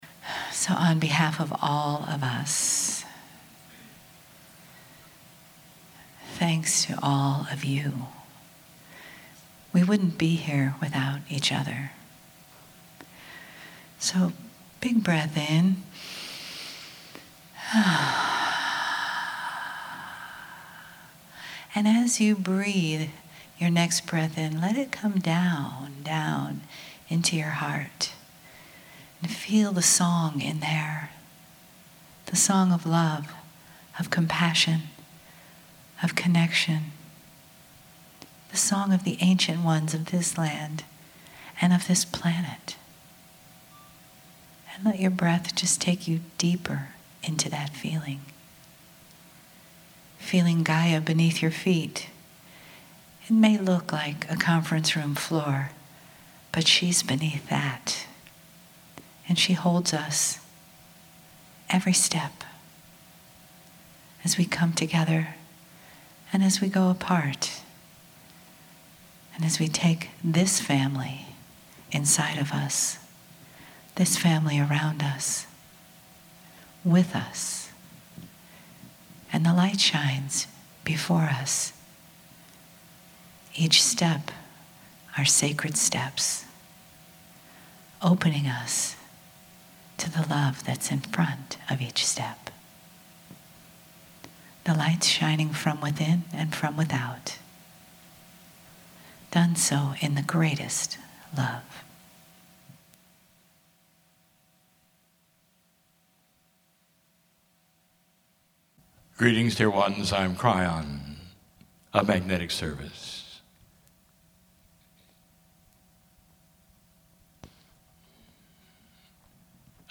KRYON CHANNELLING
Queenstown - Conf DAY 3 - Letter I